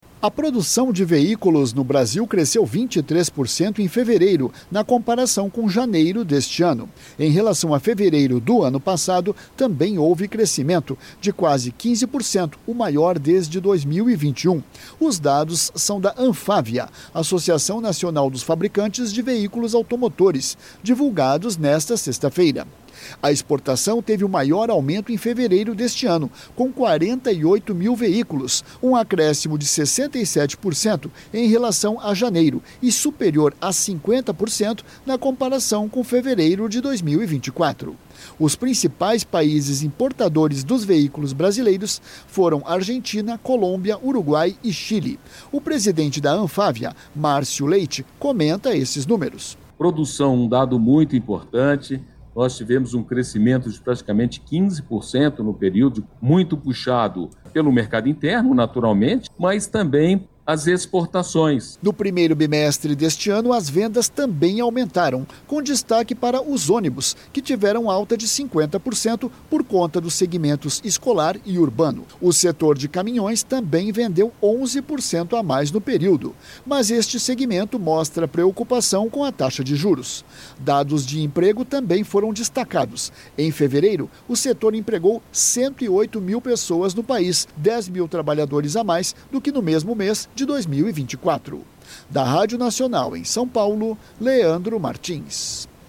São Paulo